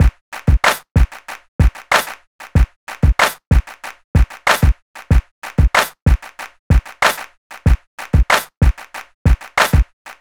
MLB Drum Loop  94 BPM.wav